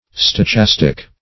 Stochastic \Sto*chas"tic\ (st[-o]*k[a^]s"t[i^]k), a. [Gr.